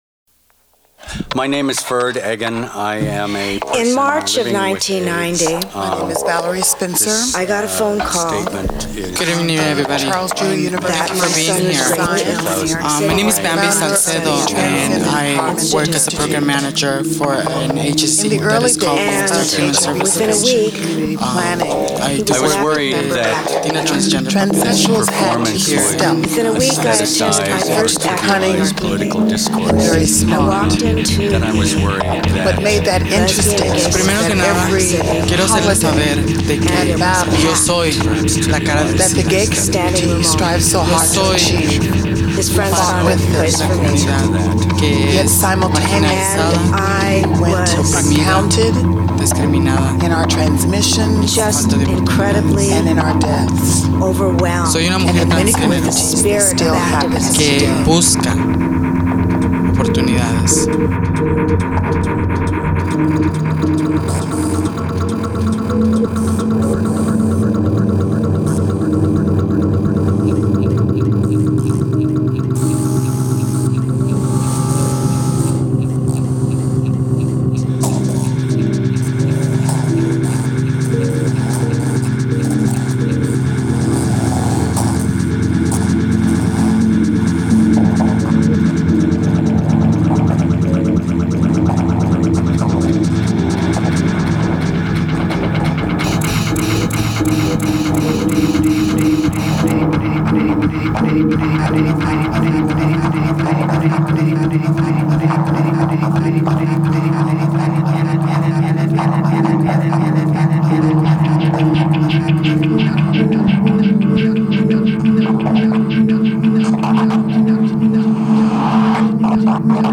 Armand Hammer Museum, Thursday, 5 May 2005